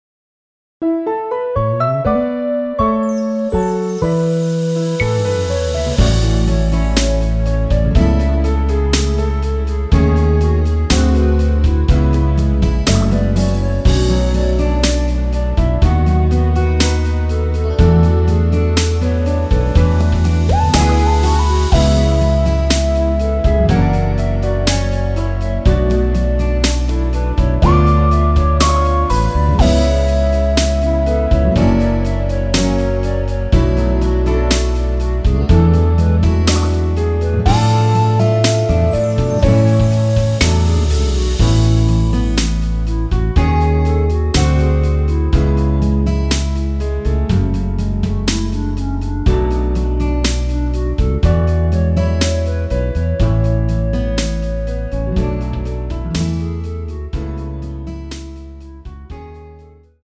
원키에서(+1)올린 멜로디 포함된 MR입니다.
앞부분30초, 뒷부분30초씩 편집해서 올려 드리고 있습니다.
중간에 음이 끈어지고 다시 나오는 이유는